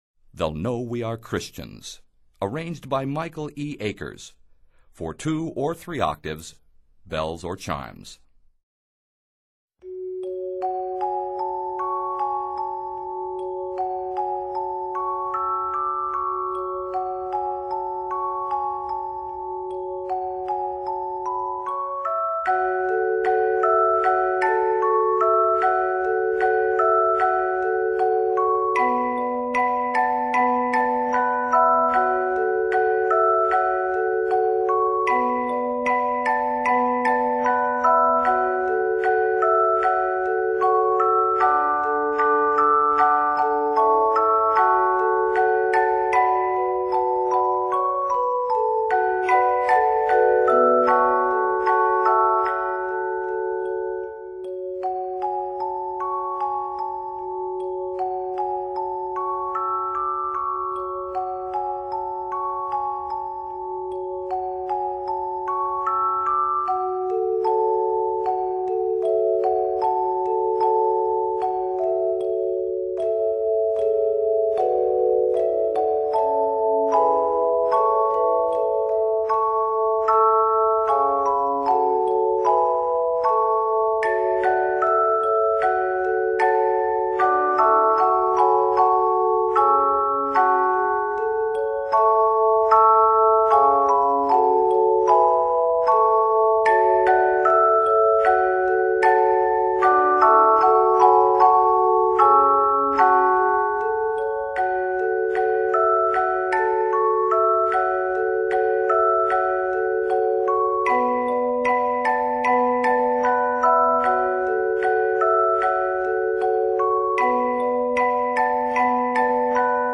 It is 45 measures long and is set in g minor throughout.